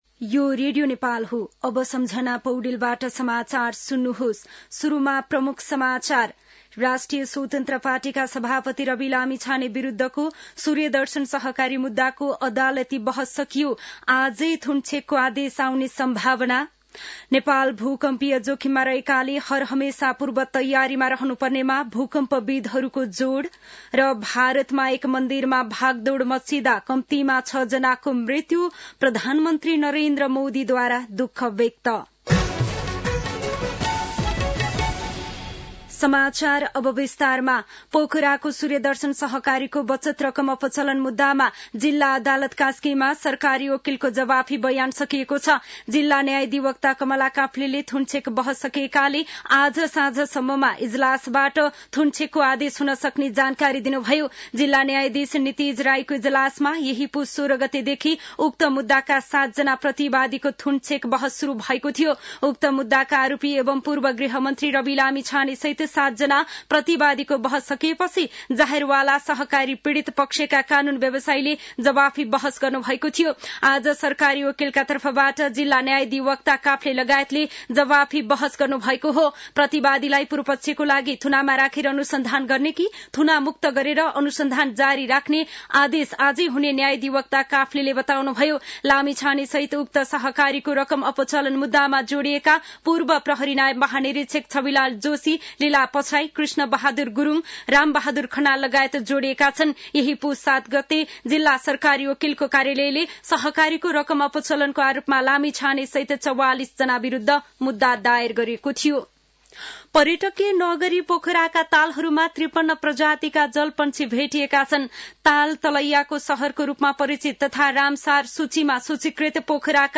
दिउँसो ३ बजेको नेपाली समाचार : २६ पुष , २०८१